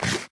eat2.ogg